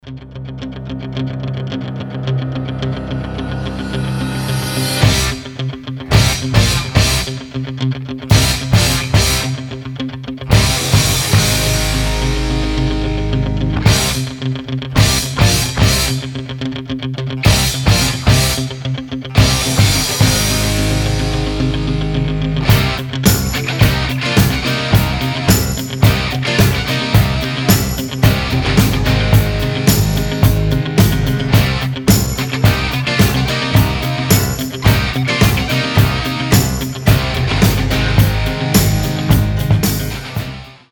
Рок рингтоны , Рингтоны без слов
Тяжелый рок